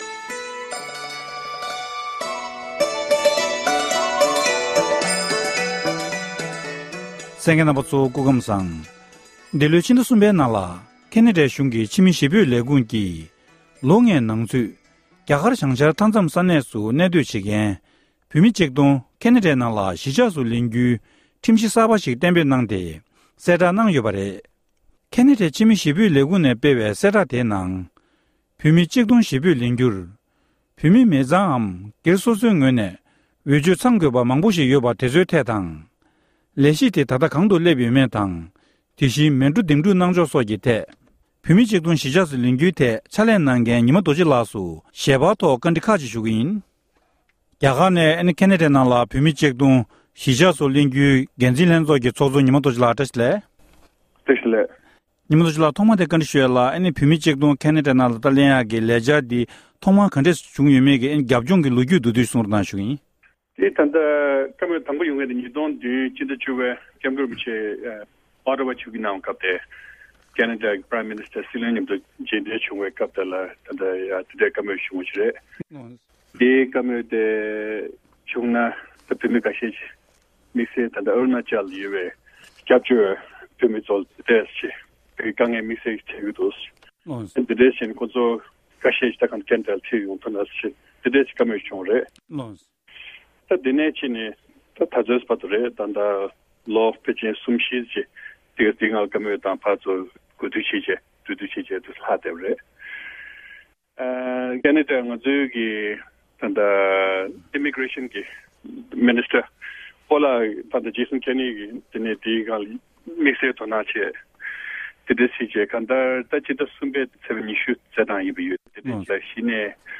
གནས་འདྲི་ཞུས་ཡོད༎